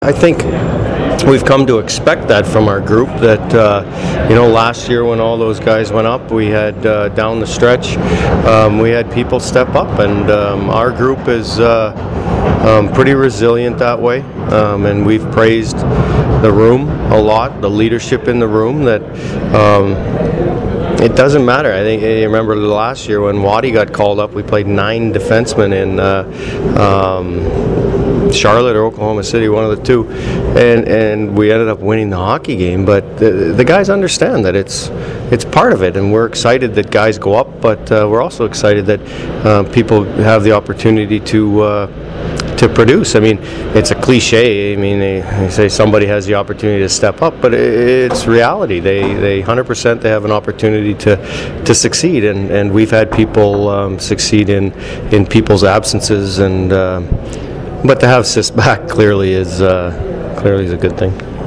Let’s dive into last night’s post-game interviews!